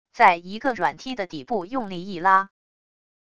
在一个软梯的底部用力一拉wav音频